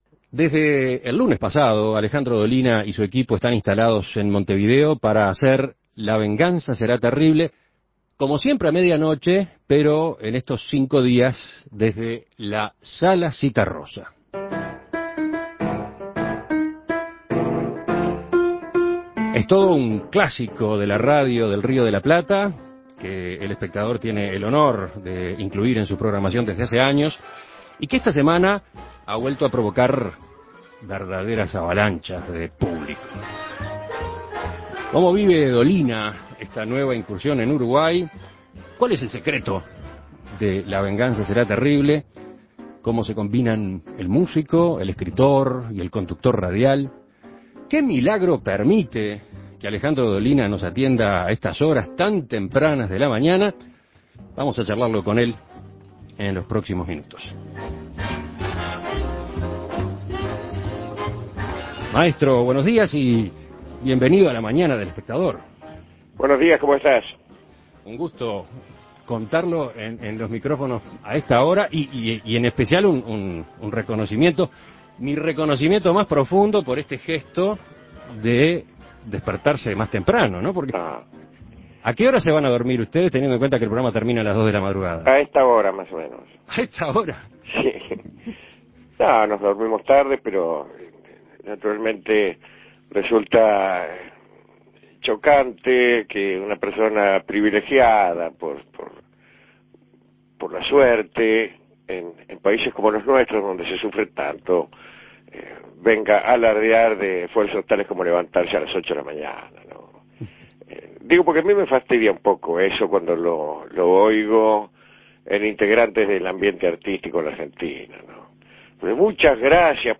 Escuche la entrevista a Alejandro Dolina